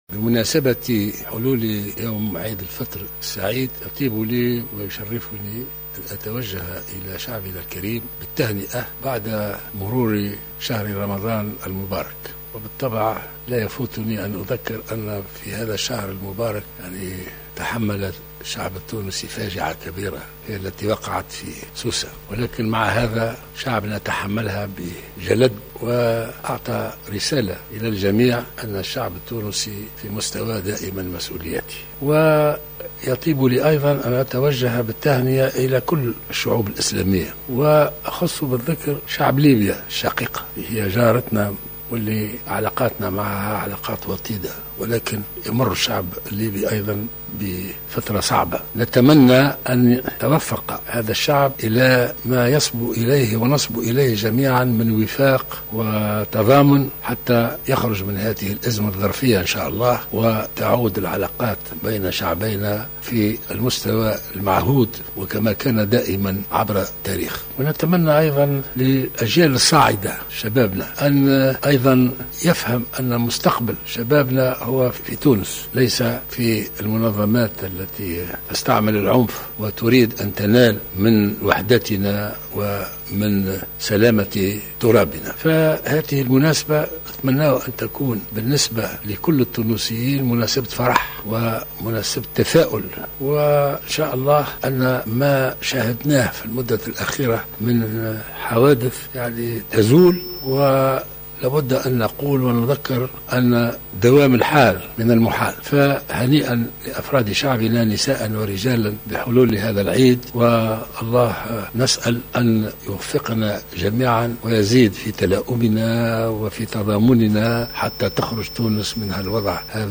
Dans son discours prononcé à l’occasion de la fête d’Aïd el Fitr, le président de la République Beji Caied Essebsi, a appelé les jeunes tunisiens à combattre l’extrémisme en toutes ses formes.